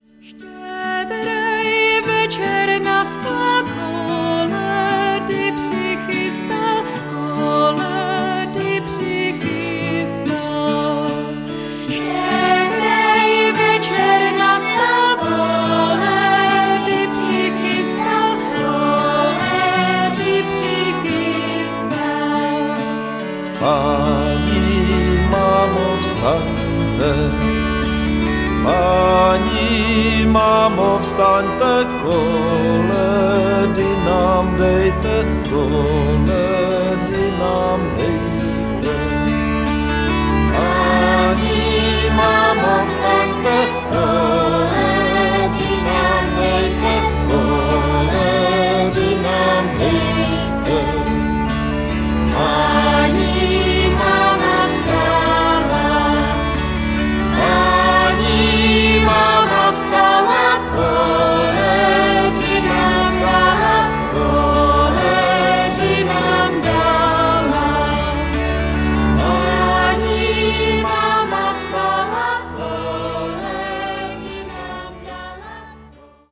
lidová